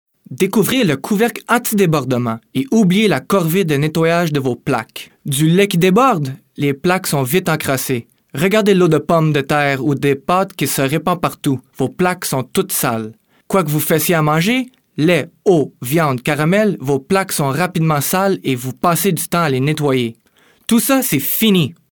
French Canadian male voice over